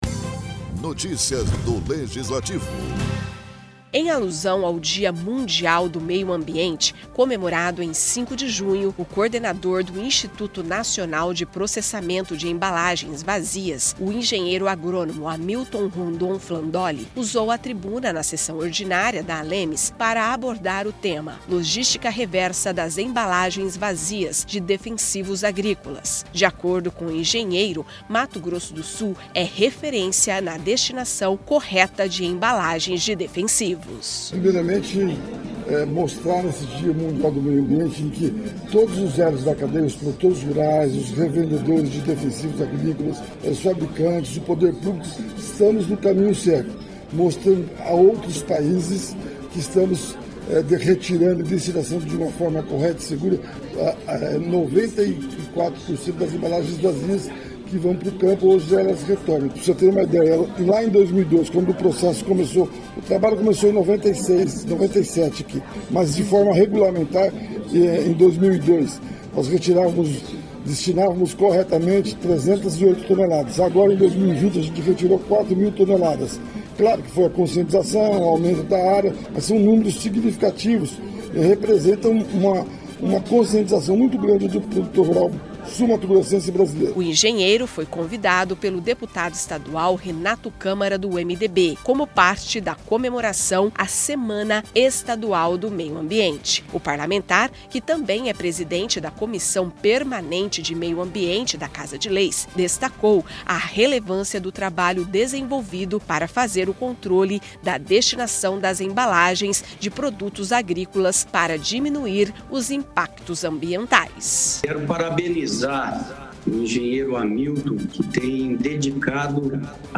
Na tribuna da ALEMS, engenheiro fala da destinação de embalagens de defensivos agrícolas